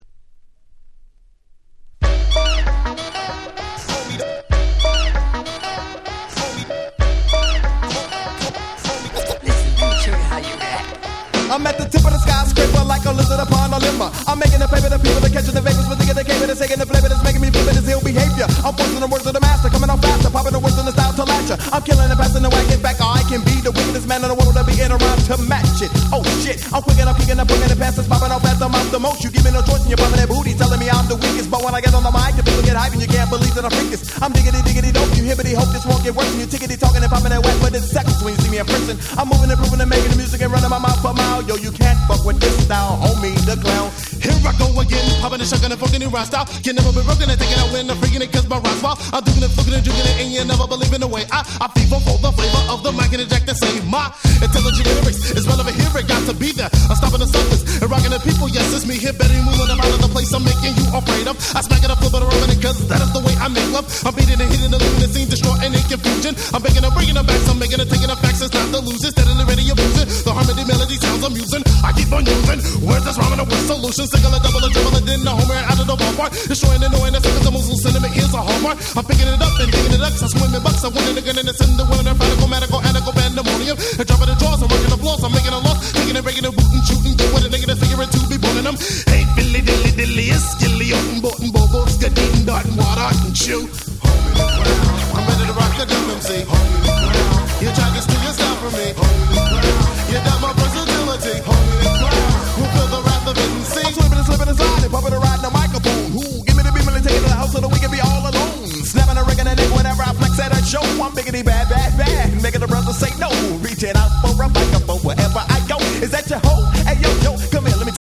92' Nice West Coast Hip Hop !!
(Vocal Mix)